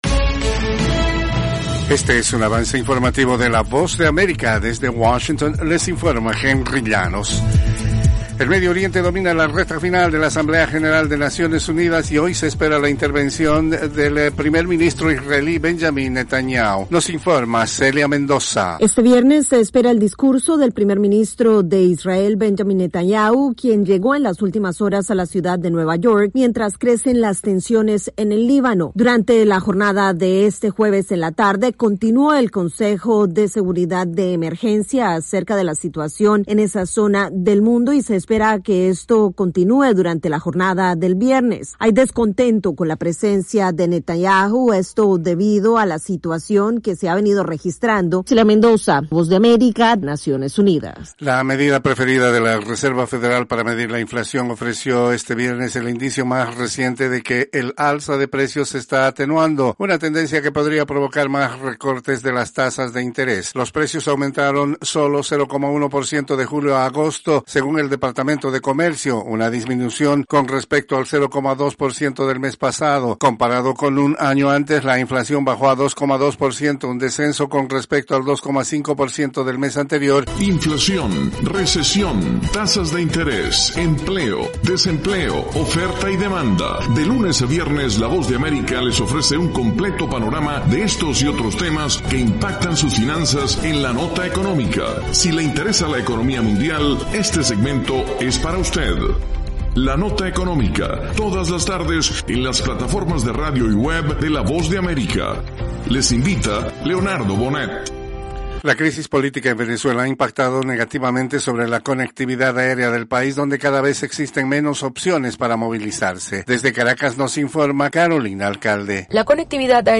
Cápsula informativa de tres minutos con el acontecer noticioso de Estados Unidos y el mundo. [10:00am Hora de Washington].